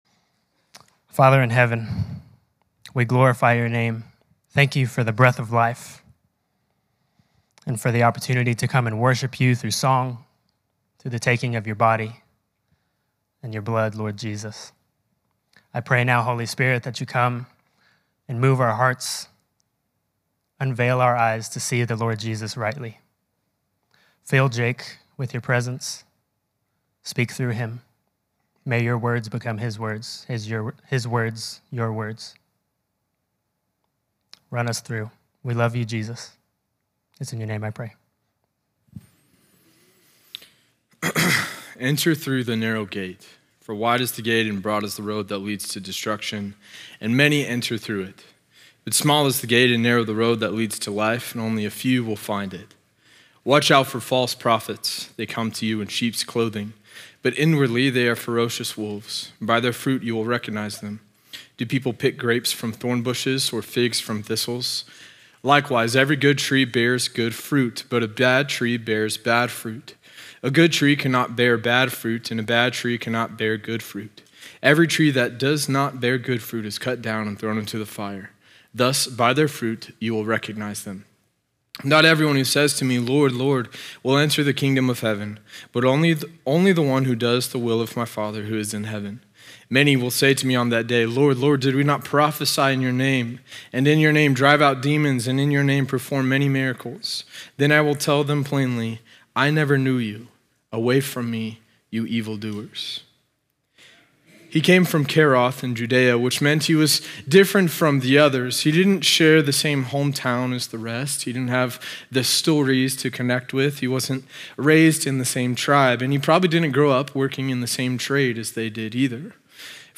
sermon audio 0824.mp3